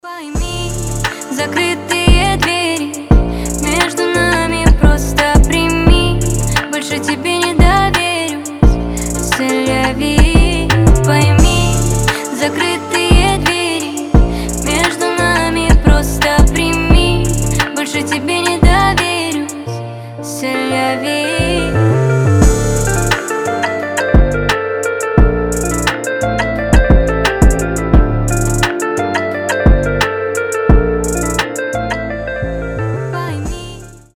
• Качество: 320, Stereo
мелодичные
красивый женский голос